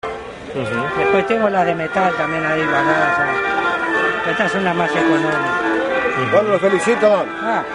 Hier ist das Grammophon zwar nicht zu sehen, aber in 5 Folgen zu hören.